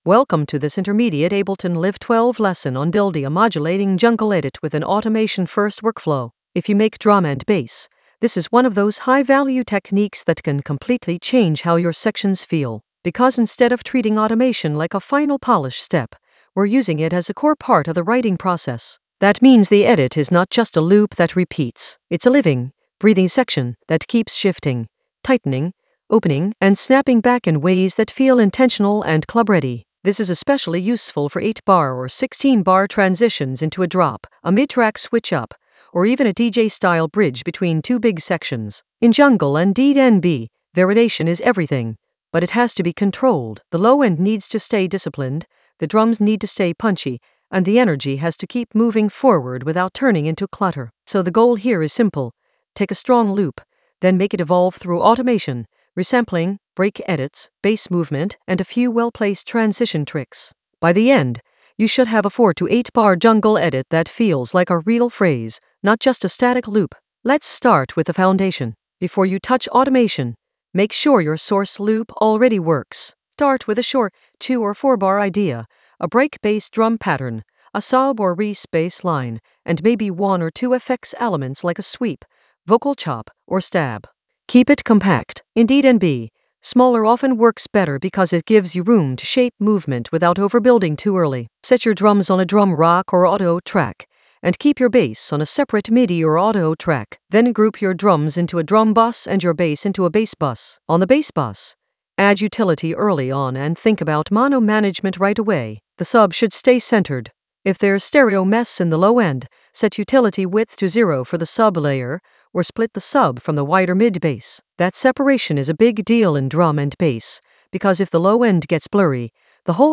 Narrated lesson audio
The voice track includes the tutorial plus extra teacher commentary.
An AI-generated intermediate Ableton lesson focused on Modulate jungle edit with automation-first workflow in Ableton Live 12 in the Edits area of drum and bass production.